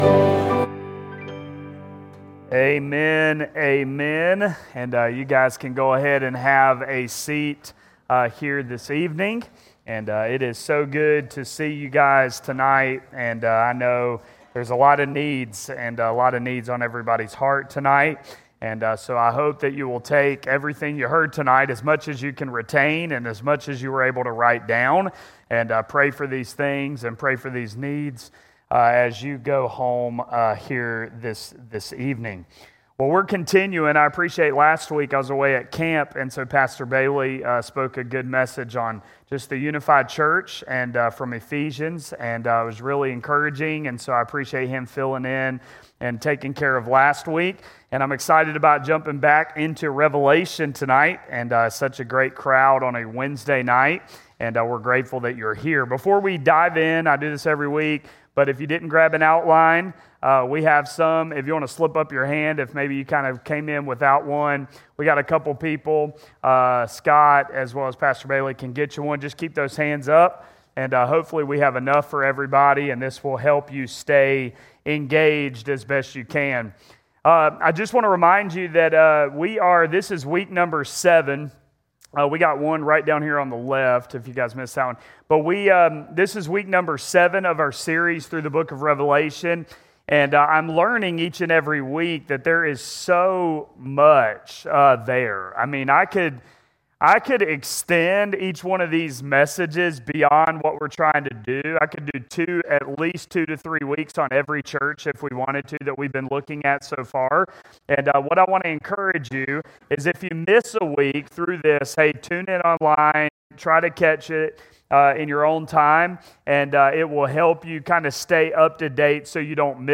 In this sermon